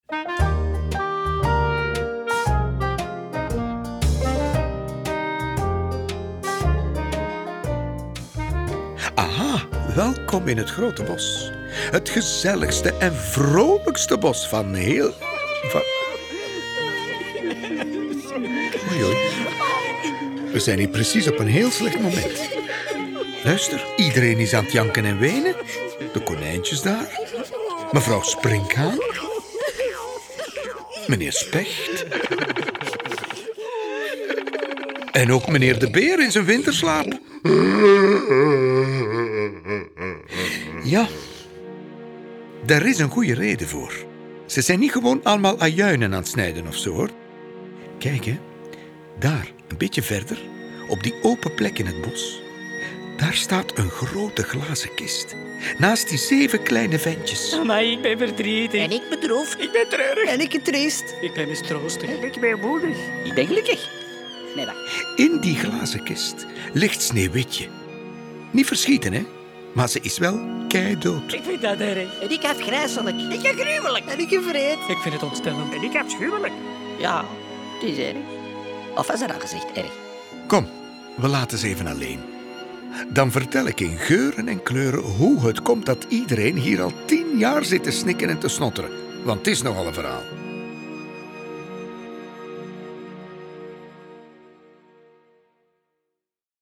Klarinet en Basklarinet
Viool